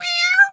pgs/Assets/Audio/Animal_Impersonations/cat_2_meow_05.wav at master
cat_2_meow_05.wav